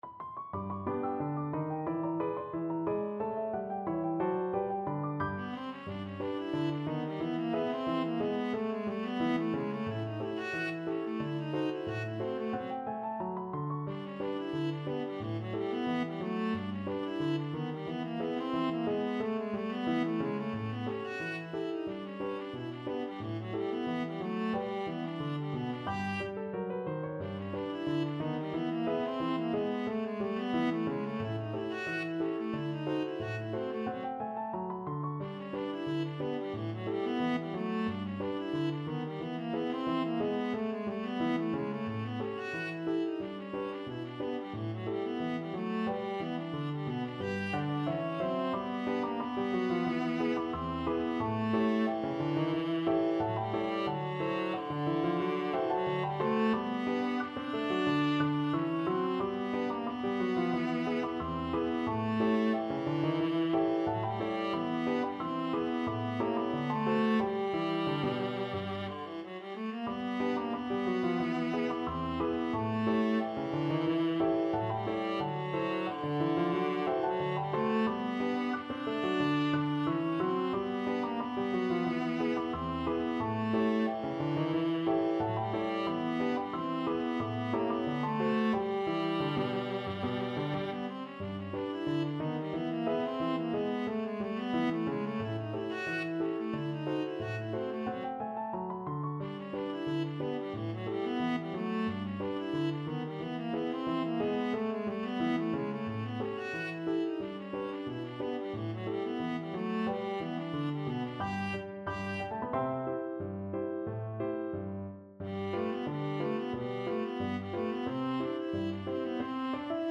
Viola
G major (Sounding Pitch) (View more G major Music for Viola )
Slow Drag. = 90
2/4 (View more 2/4 Music)
D4-C6
Jazz (View more Jazz Viola Music)